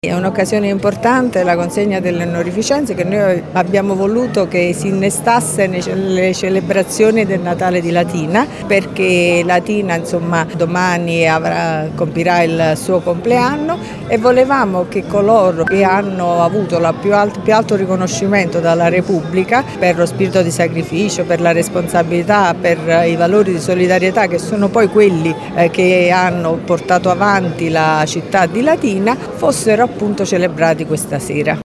La nostra città oggi compie 93 anni e ieri sera il calendario di eventi si è aperto con la cerimonia di consegna delle Onorificenze al Merito della Repubblica Italiana presso il teatro D’Annunzio. I riconoscimenti sono andati a 13 cittadini della provincia che si sono distinti per meriti civili e professionali, un’occasione voluta soprattutto dal Prefetto di Latina Vittoria Ciaramella: